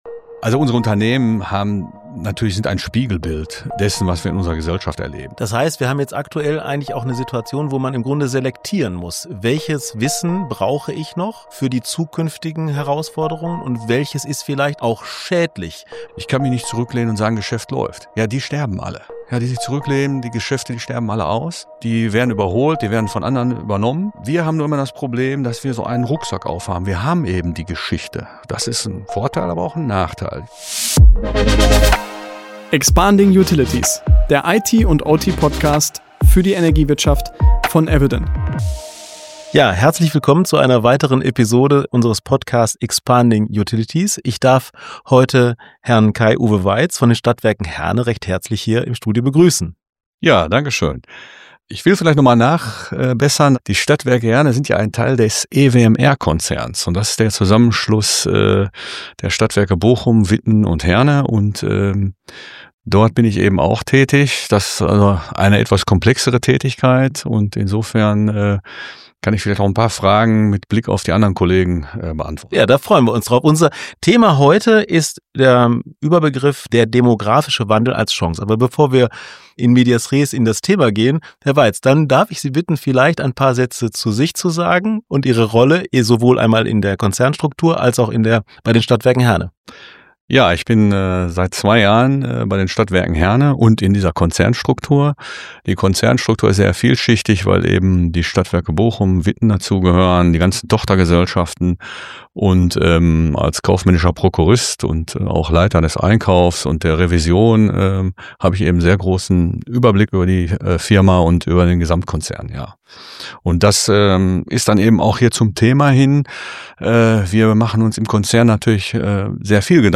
Gemeinsam sprechen sie über den demografischen Wandel und wie dieser in der Energiebranche nicht nur als Risiko, sondern vor allem als Chance gesehen werden kann.